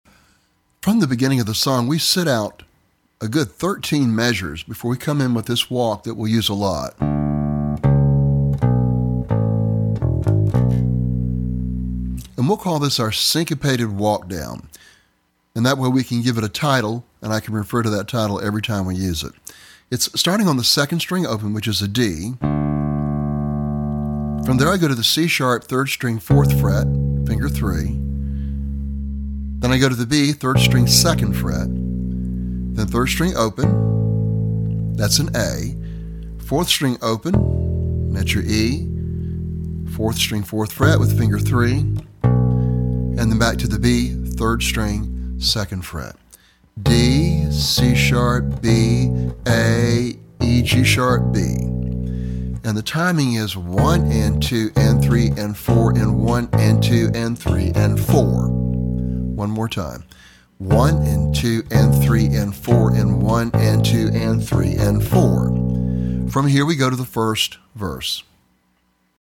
Bass Guitar
Lesson Sample
For Bass Guitar.